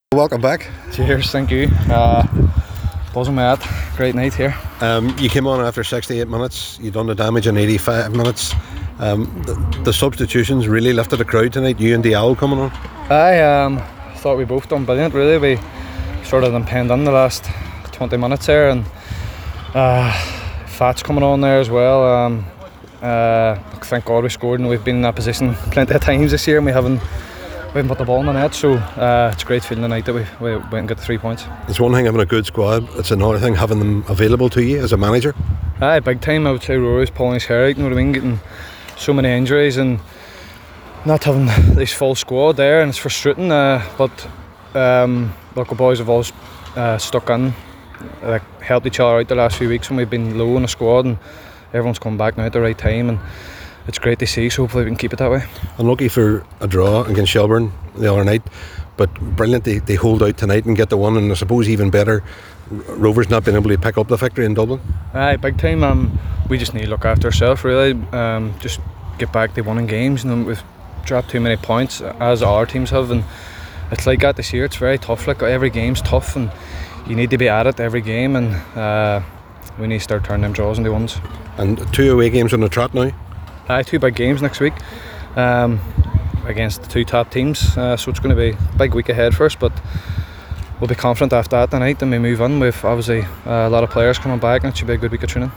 Reaction: Derry City players on their win over Bohemians